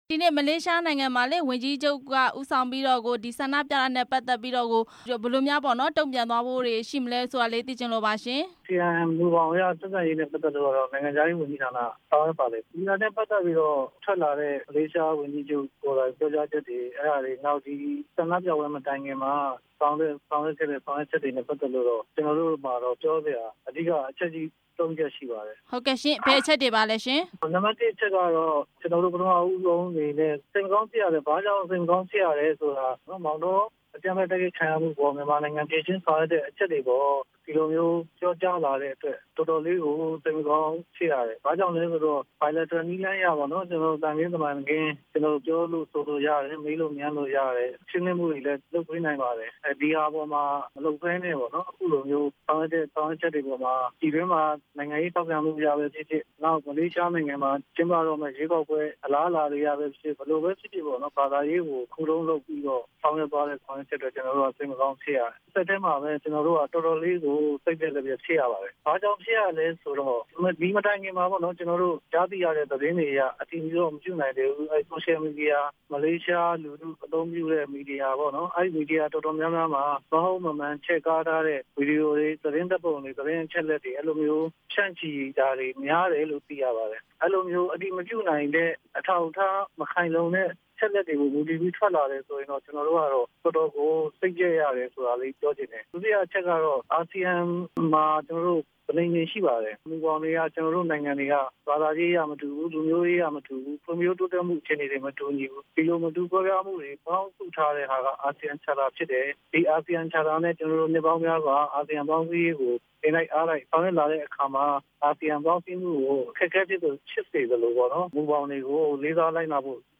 နိုင်ငံတော်သမ္မတရုံး ပြောရေးဆိုခွင့်ရှိသူ ဦးဇော်ဌေးနဲ့ မေးမြန်းချက်